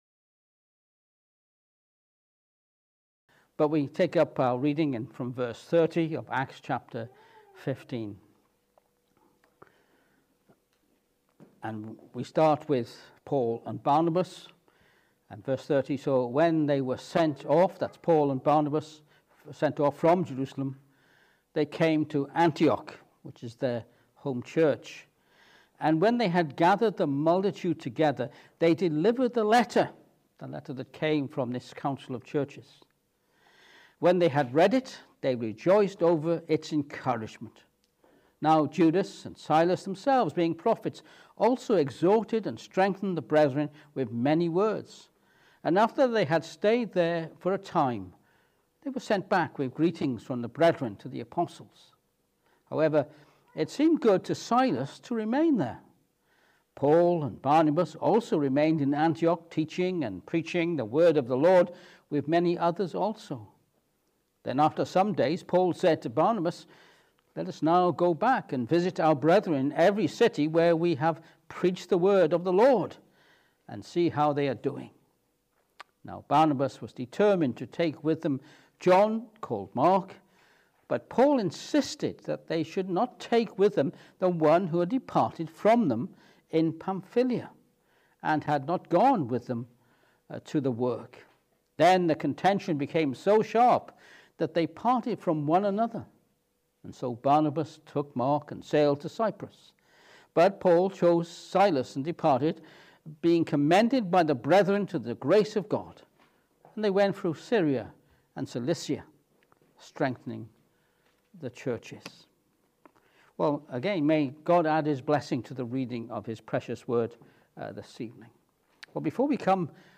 Acts 15:30-40 Service Type: Evening Service Paul and Barnabas return from Jerusalem and plan their next missionary journey in Acts 15:30-40.